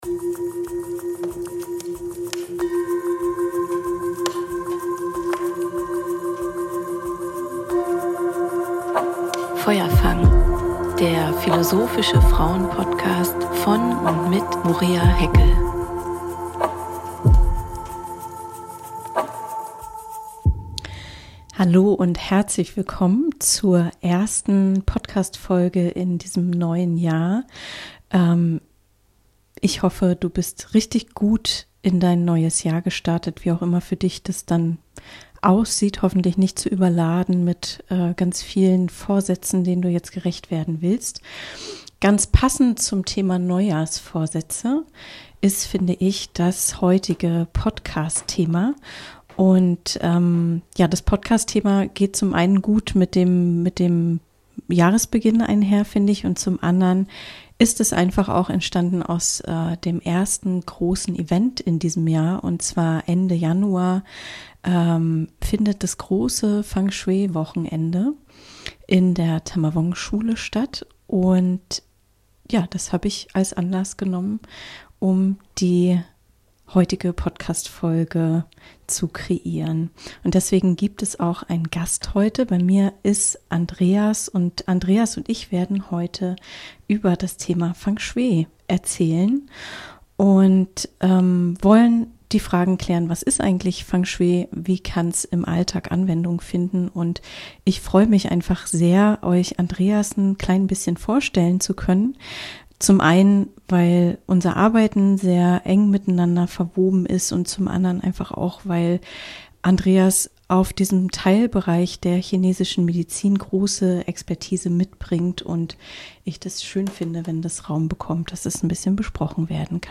ihn vorstellen und interviewen zu können rund um das Thema Feng Shui.